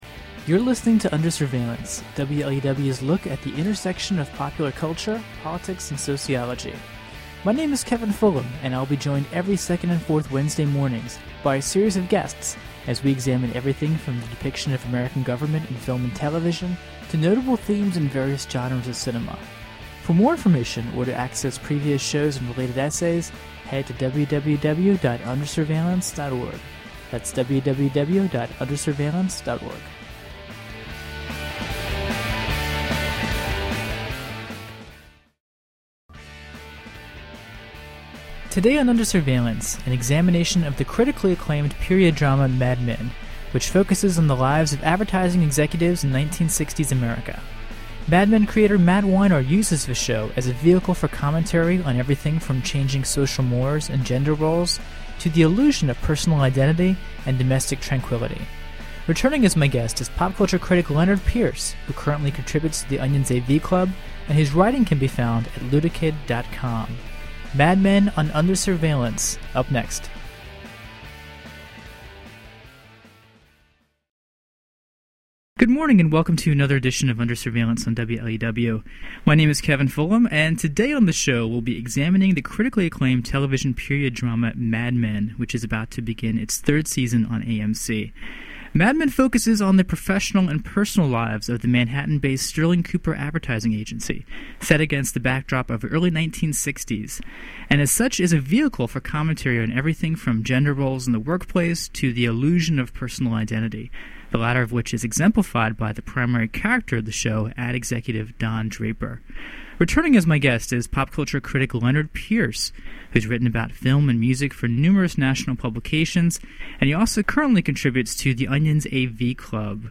[Originally broadcast on WLUW’s Under Surveillance in August 2009.] https